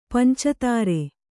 ♪ panca tāre